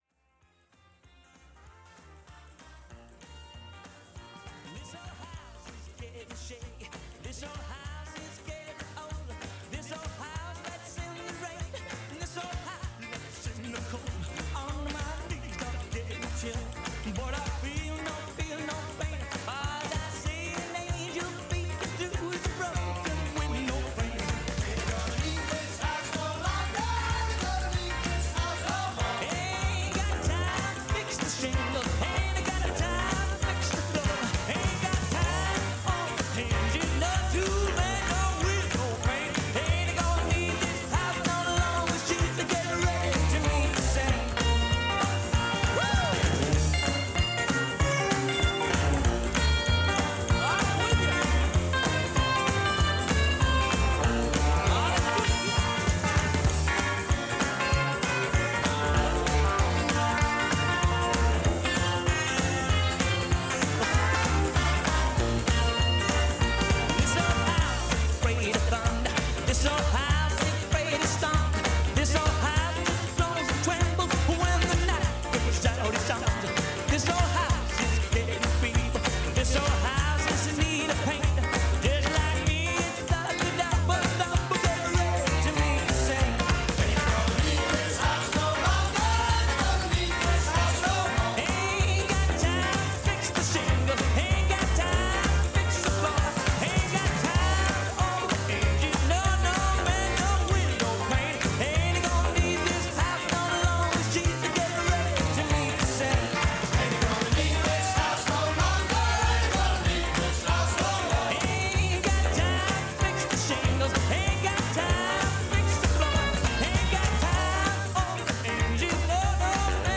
LCR Lincoln interview - Nettleham Woodland Trust